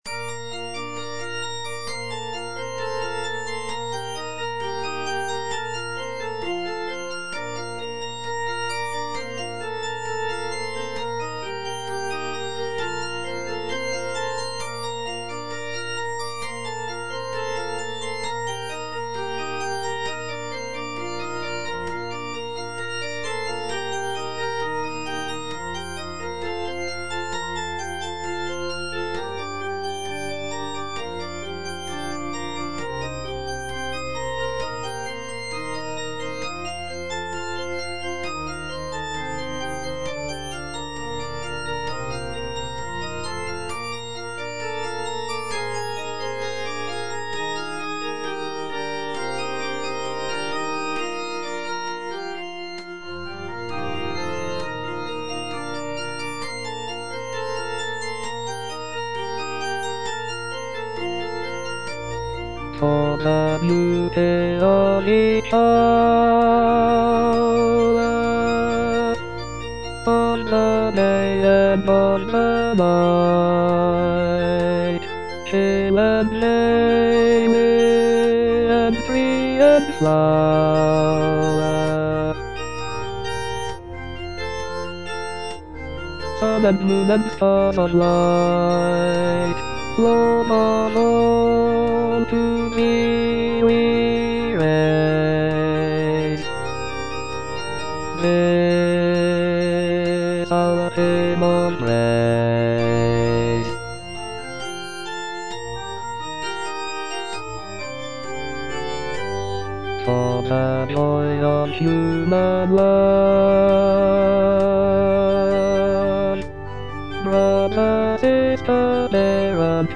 Bass II (Voice with metronome)